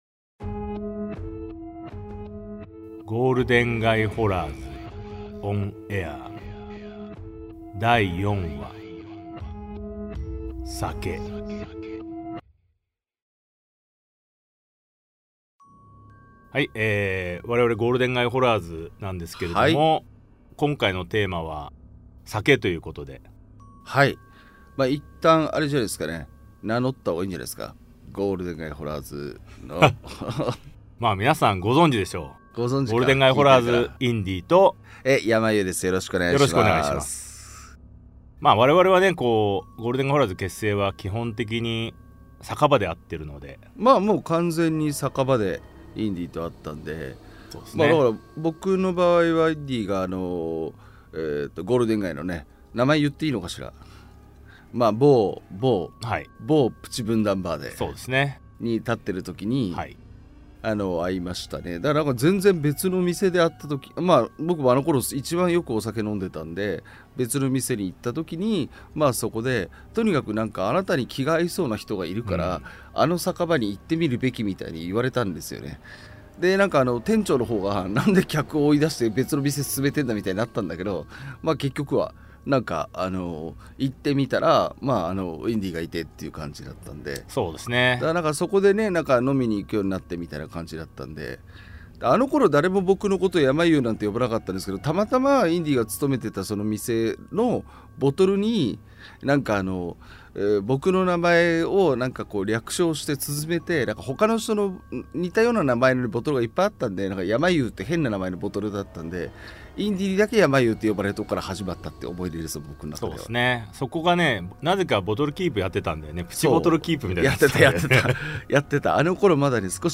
[オーディオブック] ゴールデン街ホラーズ ON AIR vol.04 酒